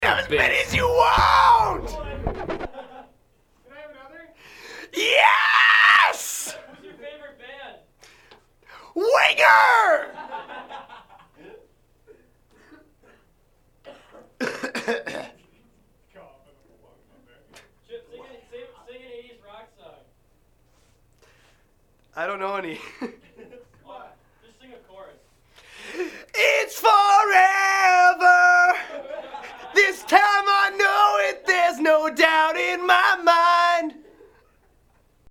doing a quick mic check before recording.